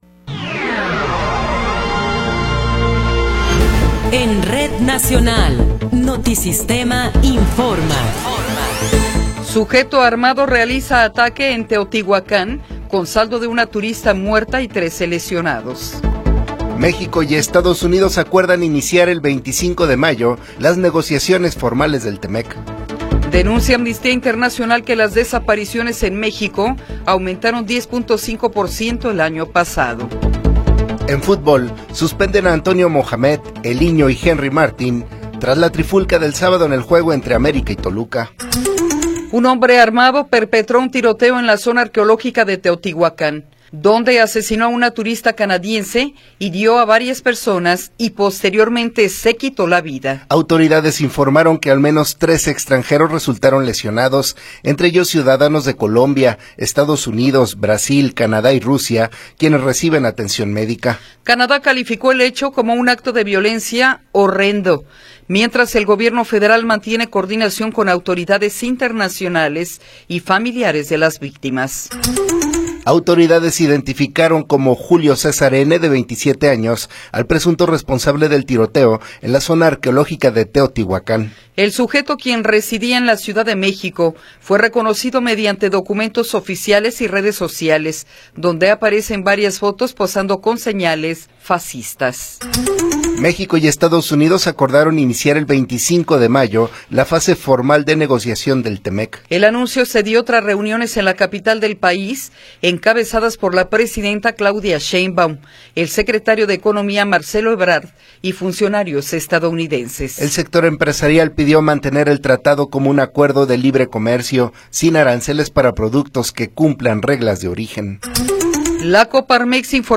Noticiero 8 hrs. – 21 de Abril de 2026